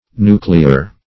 Nuclear \Nu"cle*ar\, a.